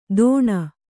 ♪ dōṇa